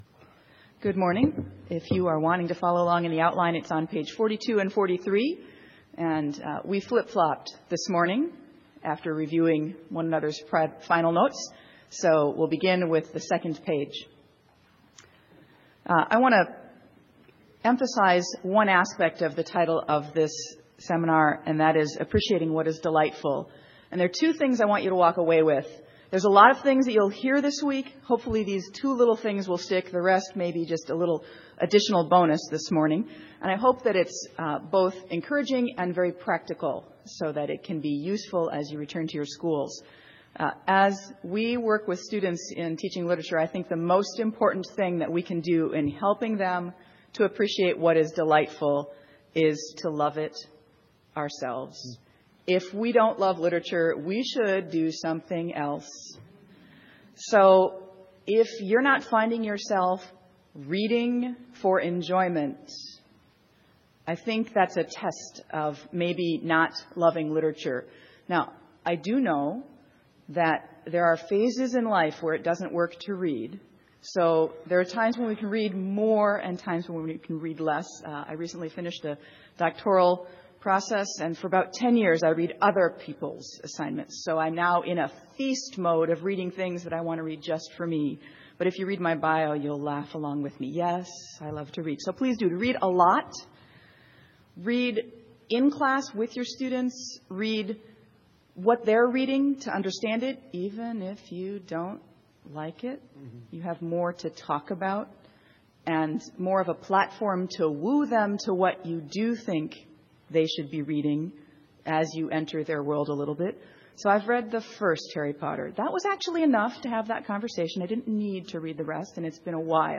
2012 Workshop Talk | 1:04:04 | All Grade Levels, Literature
Each panelist will make opening remarks. Following these remarks, the panel will answer questions from the audience.